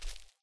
Footstep_soft.ogg